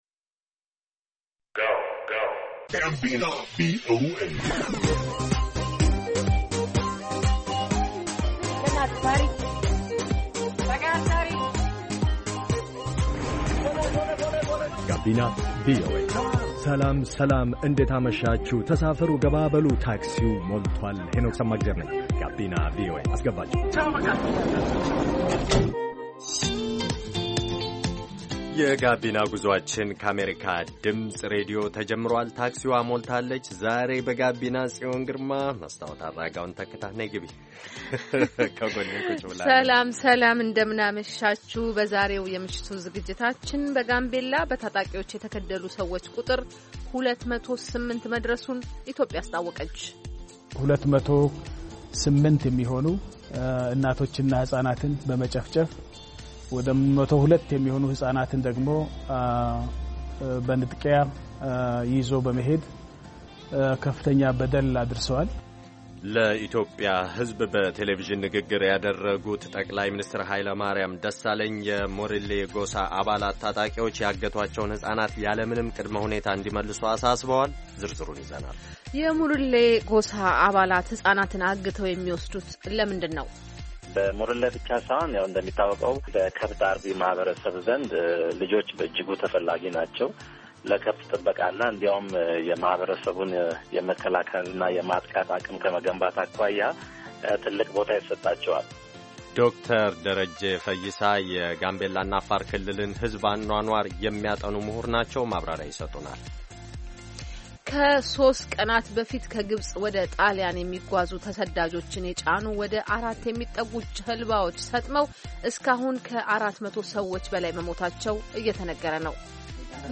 Gabina VOA is designed to be an infotainment youth radio show broadcasting to Ethiopia and Eritrea in the Amharic language. The show brings varied perspectives on issues concerning young people in the Horn of Africa region.